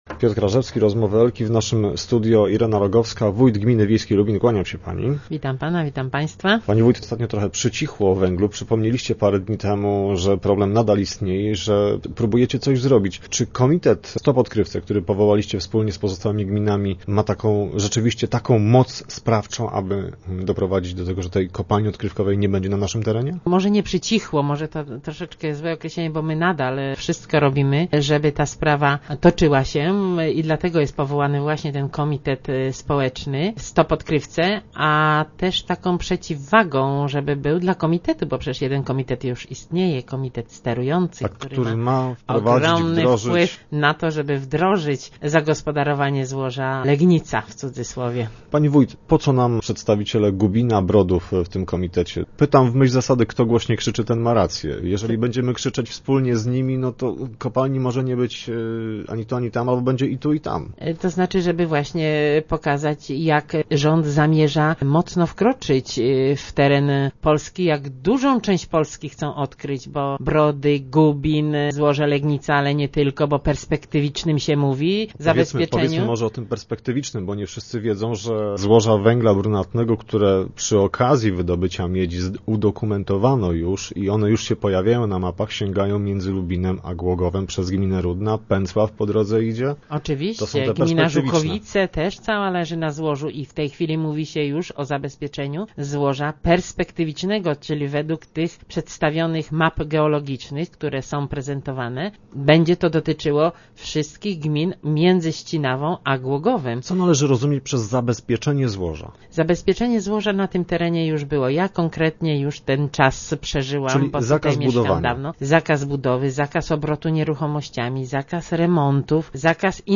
Irena Rogowska, wójt gminy Lubin mówi o nowej antyodkrywkowej inicjatywie.